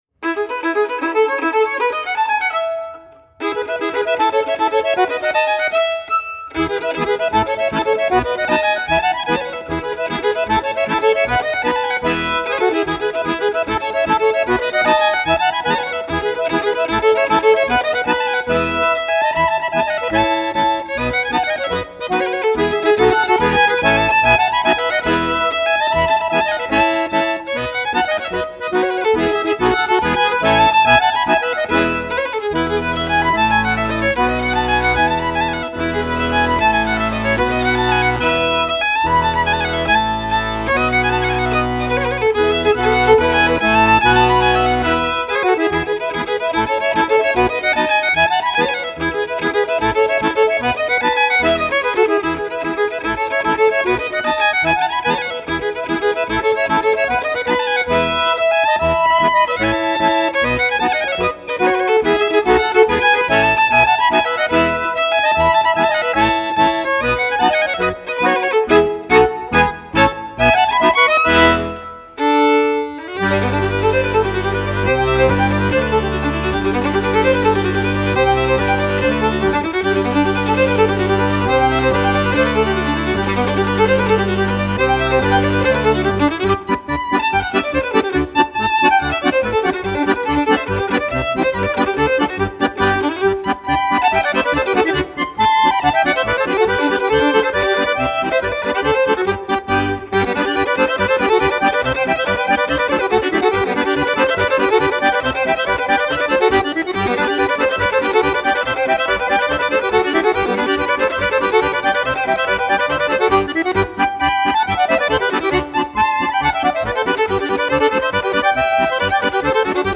Violine, Gesang, Gitarre
Akkordeon, Gesang, Gitarre